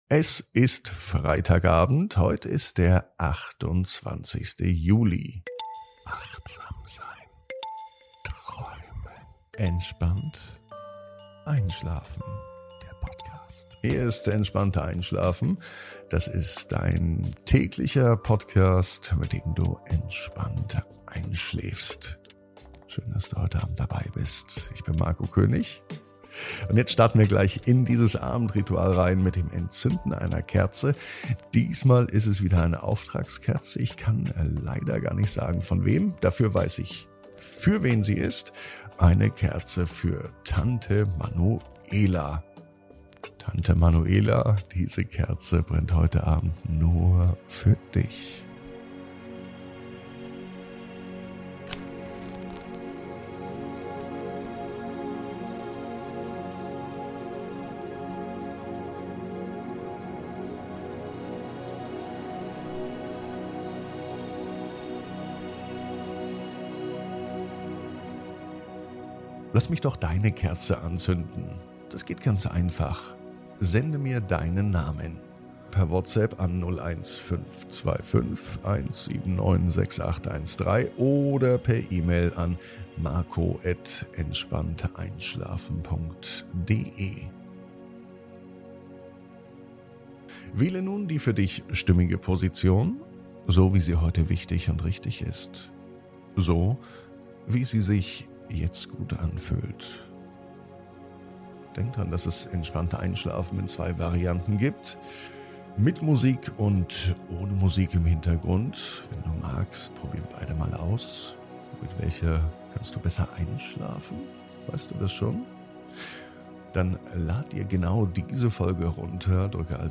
Spüre die sanfte Brise auf deiner Haut und lausche dem beruhigenden Rauschen des Flusses. Atme tief ein und aus, während du dich von den Klängen der Natur tragen lässt. Diese Traumreise ist eine Einladung, deinen Geist zu beruhigen und deinen Körper zu entspannen.